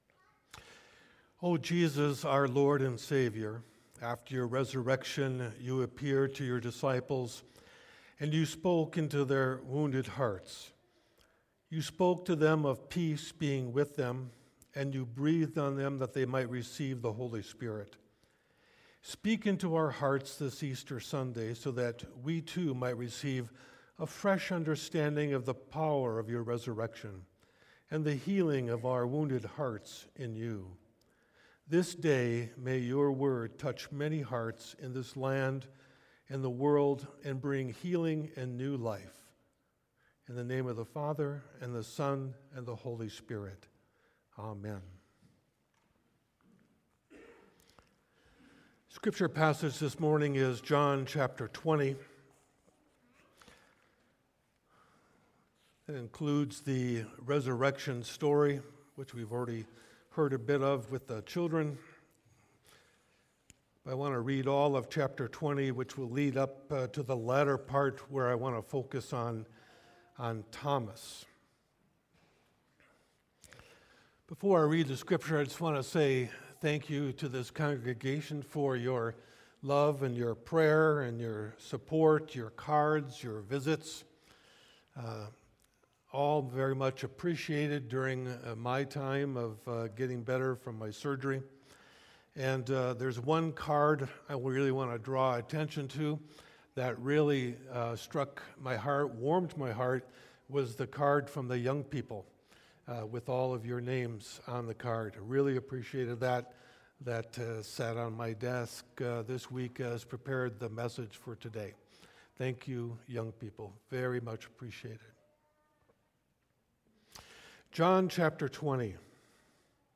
Sermons | Ebenezer Christian Reformed Church
Guest Speaker